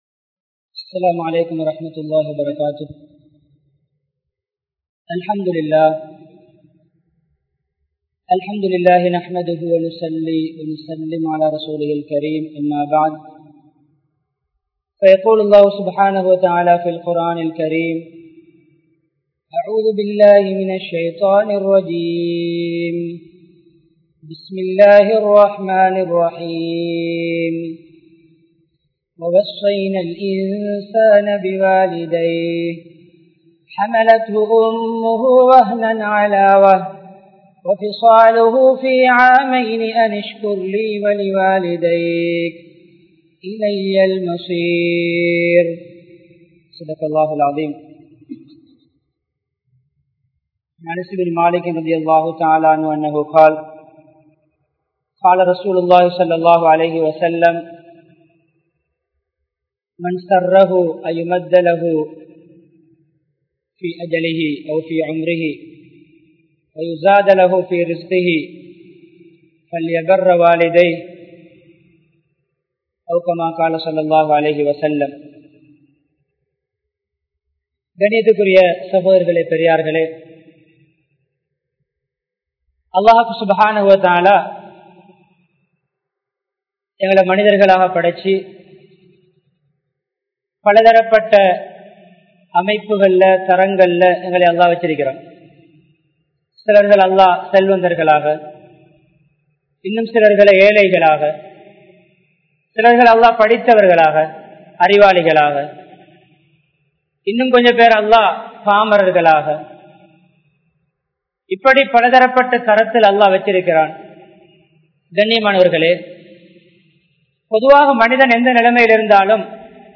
Ungalin Petroarhalai Maranthu Vidaatheerhal (உங்களின் பெற்றோர்களை மறந்து விடாதீர்கள்) | Audio Bayans | All Ceylon Muslim Youth Community | Addalaichenai
Colombo 12, Aluthkade, Muhiyadeen Jumua Masjidh